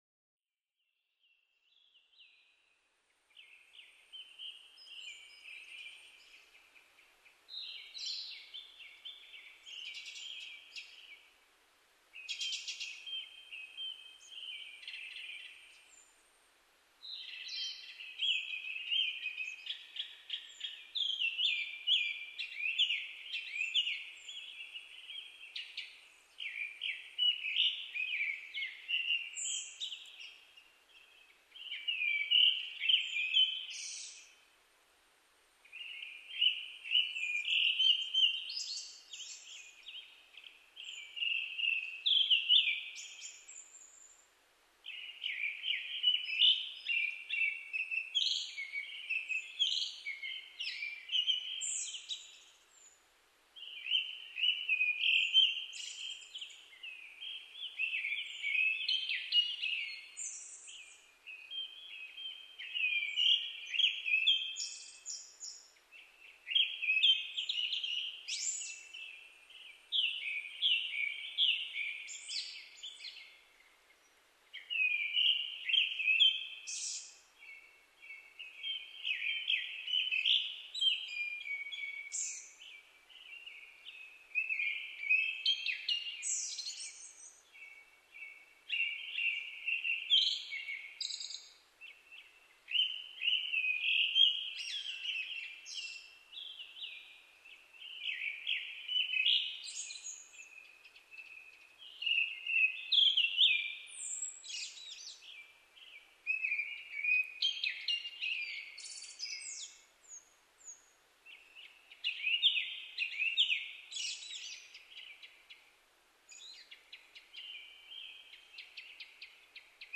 クロツグミ　Turdus cardisツグミ科
日光市稲荷川中流　alt=780m
Mic: Panasonic WM-61A  Binaural Souce with Dummy Head
日没間際のクロツグミです。
他の自然音：ヤブサメ、ツツドリ